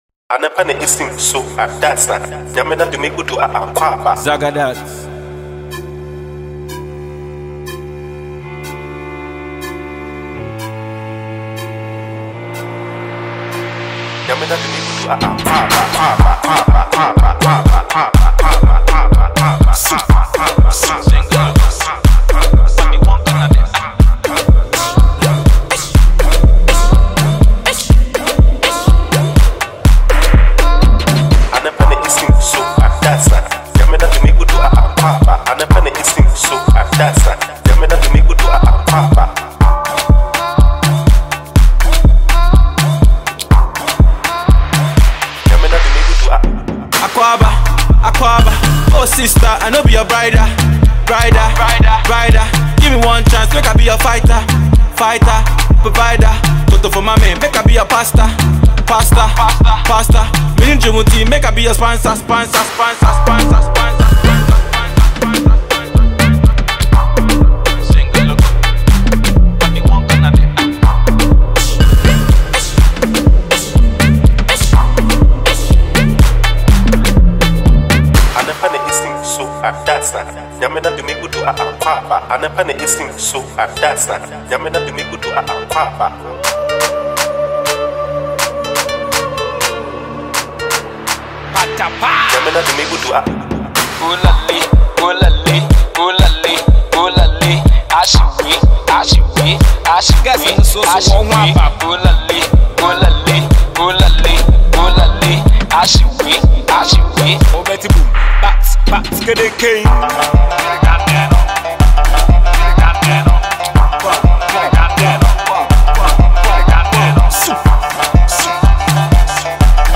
an infectious dance tune